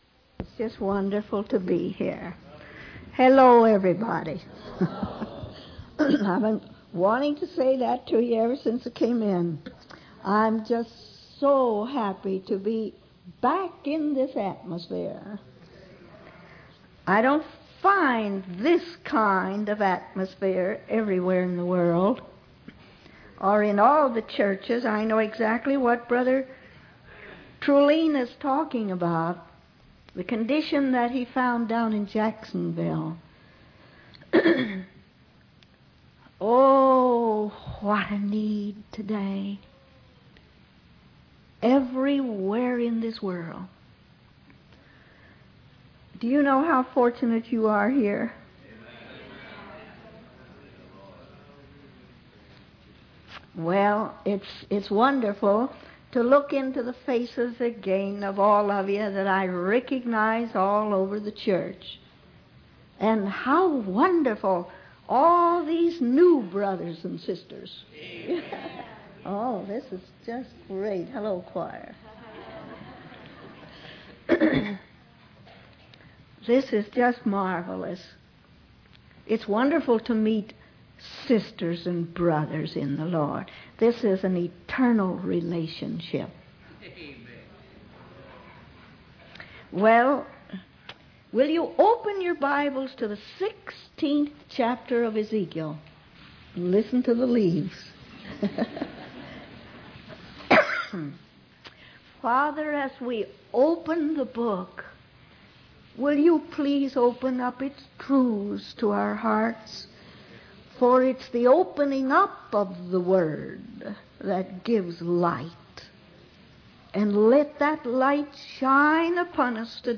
In this sermon, the preacher emphasizes the importance of dying to oneself in order to experience true life and growth in God. He shares testimonies of miraculous healings and the baptism in the Holy Spirit. The preacher encourages the congregation to come forward and accept Jesus as their Lord and Savior, promising that he will speak the word of life to them and bring about a marvelous change in their lives.